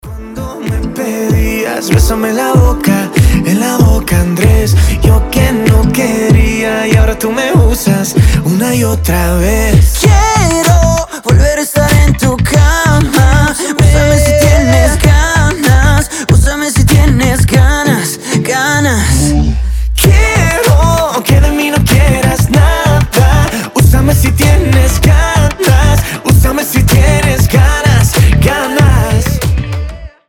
• Качество: 320, Stereo
мужской голос
ритмичные
заводные